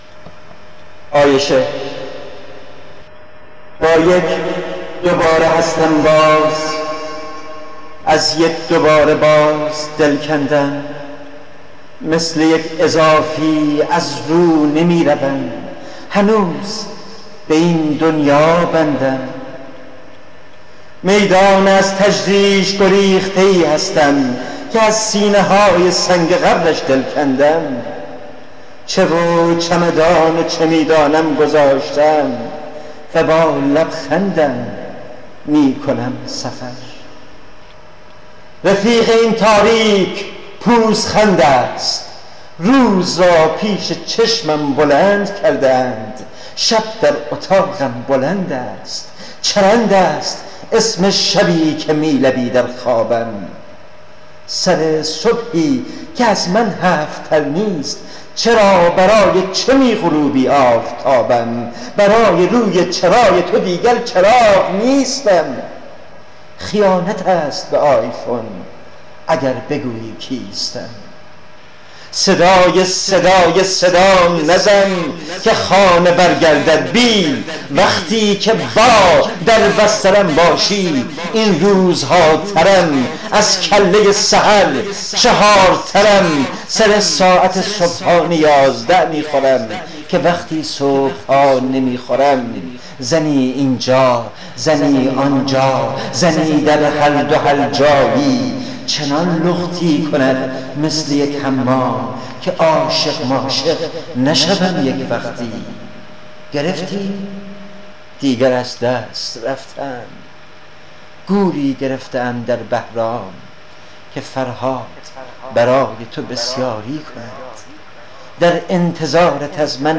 برای شنیدن این شعر با صدای شاعر